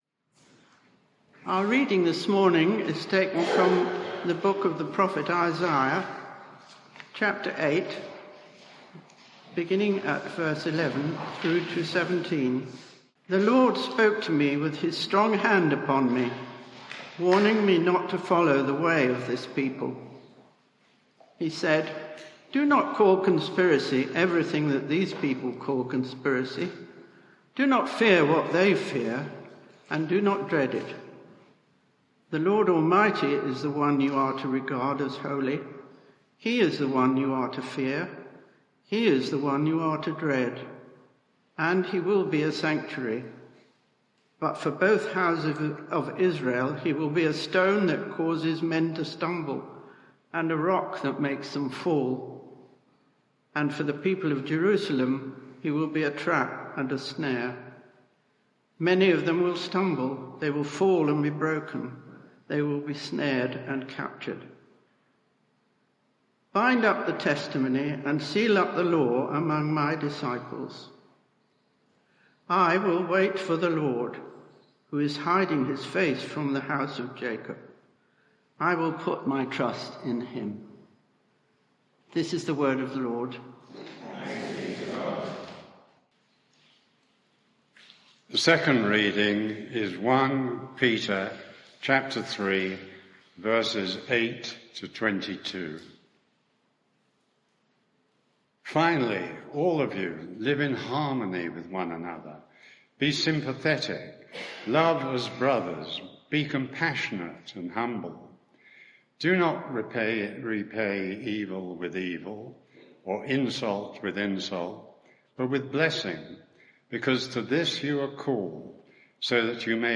Media for 11am Service on Sun 18th Jun 2023 11:00 Speaker
Sermon (audio)